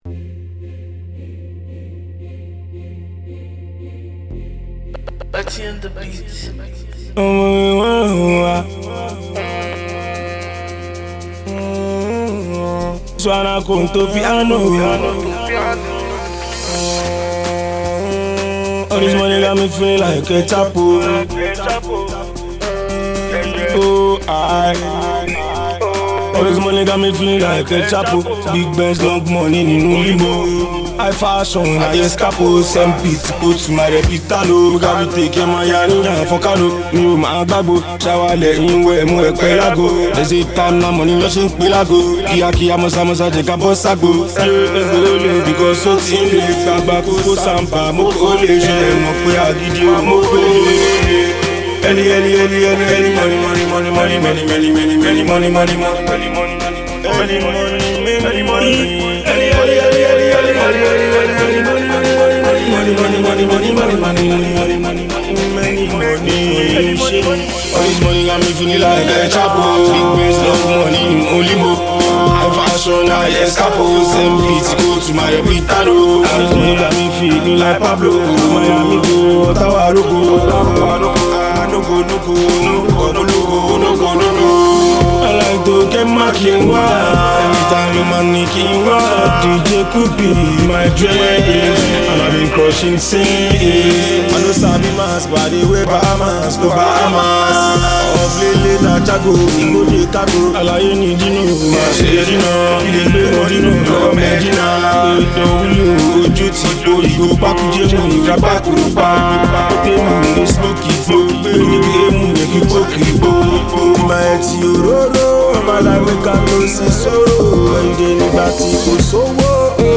Within Nigeria’s ever-evolving Afrobeats movement